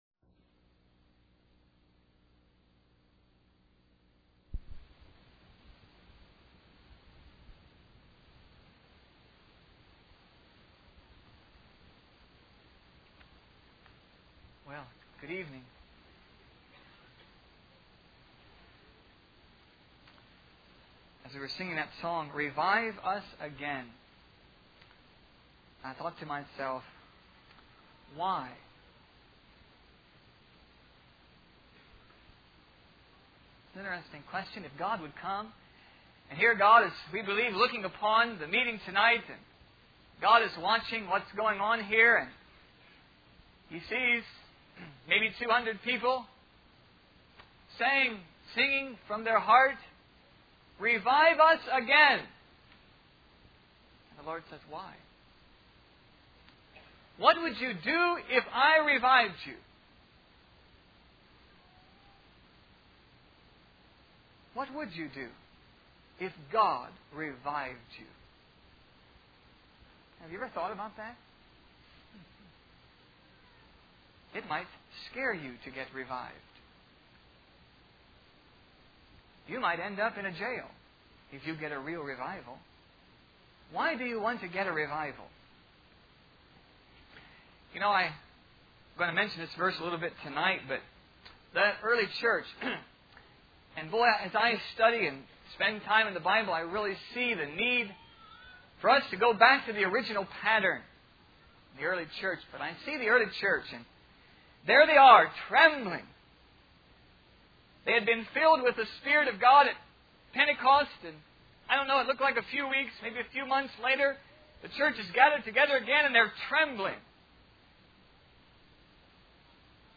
In this sermon, the preacher shares the story of Peter Cartwright, a Backwoods Methodist preacher who found himself stuck in an inn during a storm.